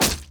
Torch Impact 2.ogg